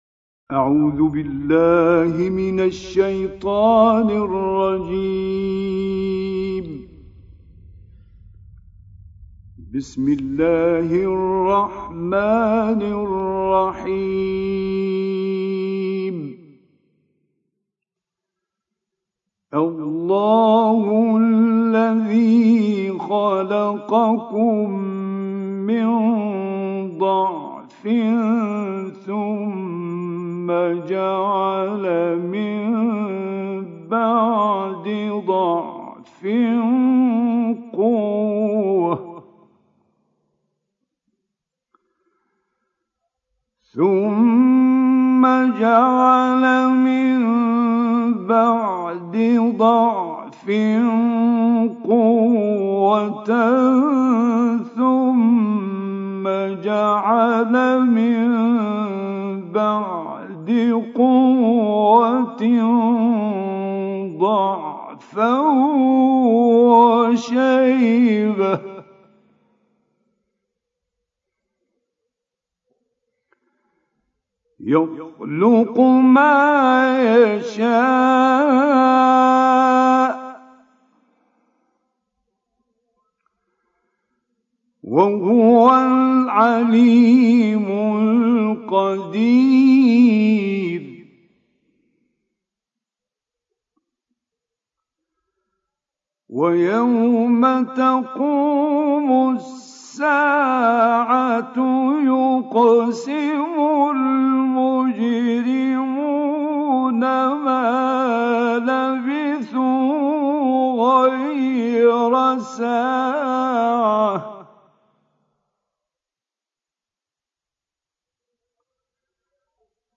تلاوت آیاتی از سوره هود و لقمان توسط مرحوم راغب مصطفی غلوش
در ادامه تلاوت سوره هود ،آیه 54 تا آخر و سوره لقمان ،آیه 1 تا 30 را توسط قاری مصری ، مرحوم راغب مصطفی غلوش می شنوید.